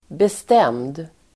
Uttal: [best'em:d]